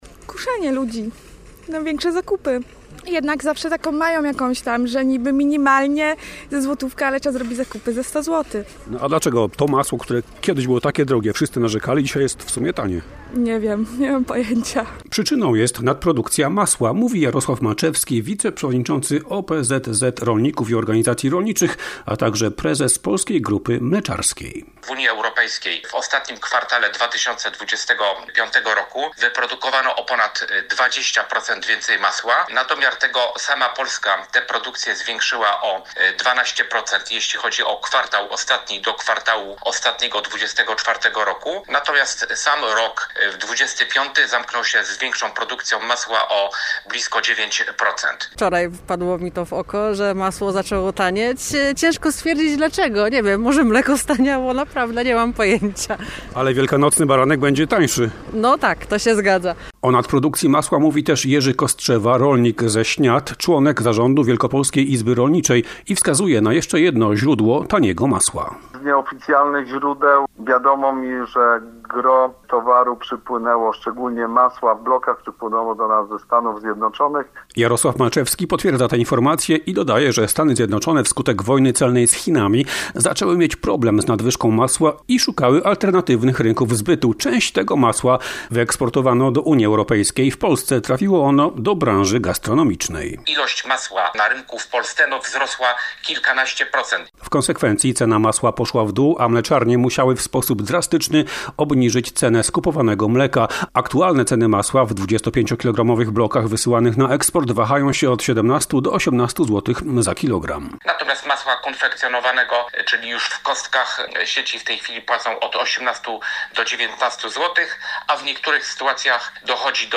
- komentują mieszkańcy Czarnkowa.